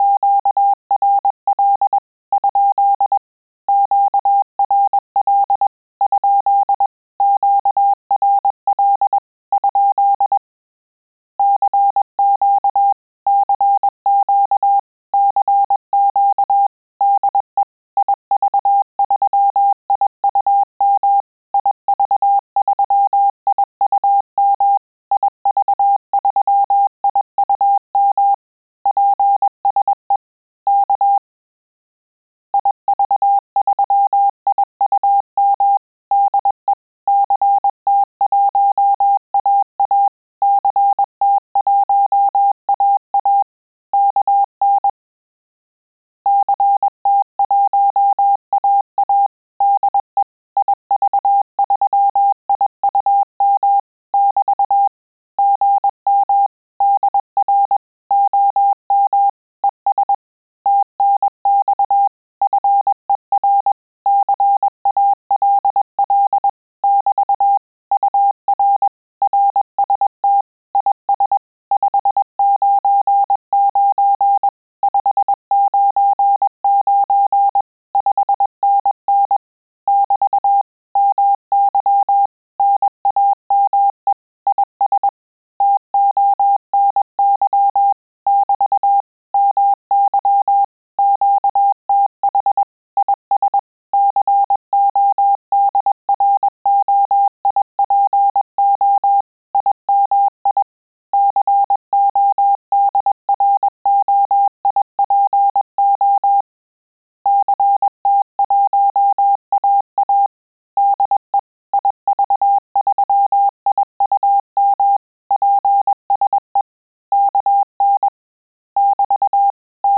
QSO CW - ITA - EN
QSO_CW_20_PPM.wav